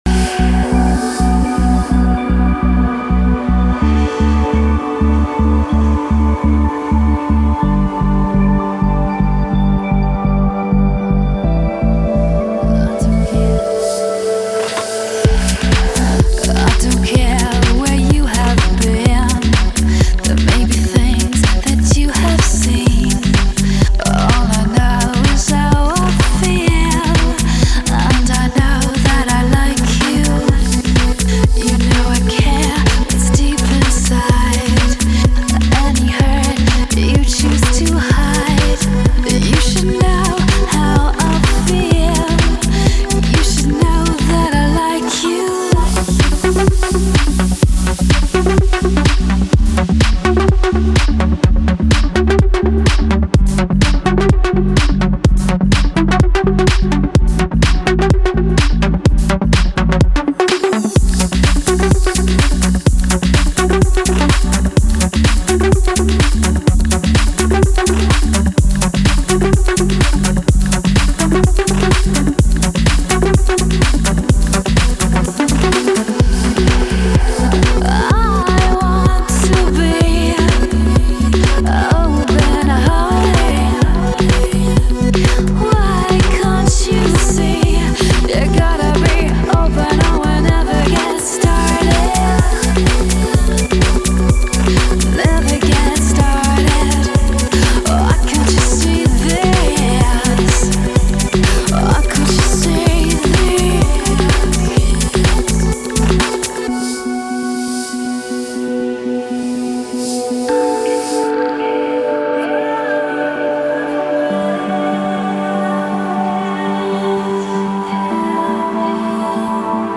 Жанр: Trance
лучшая транс вокал музыка